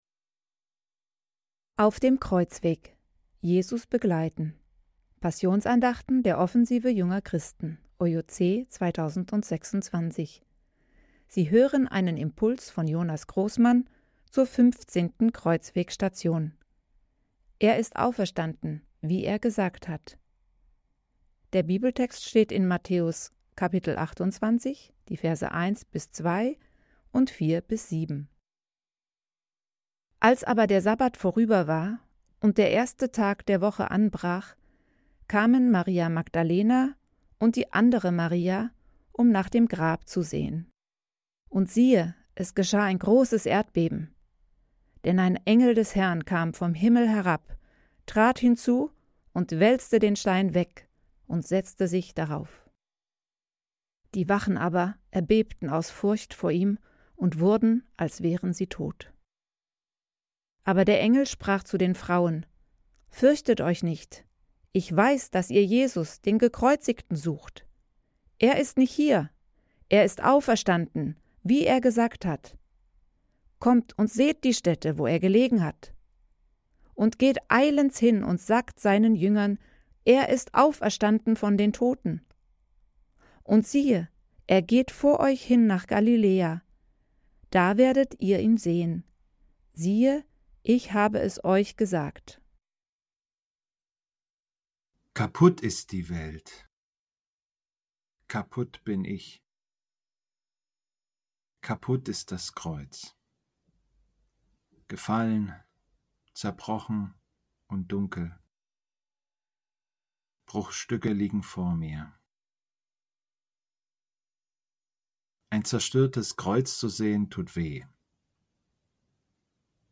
15. Station der Passionsandachten 2026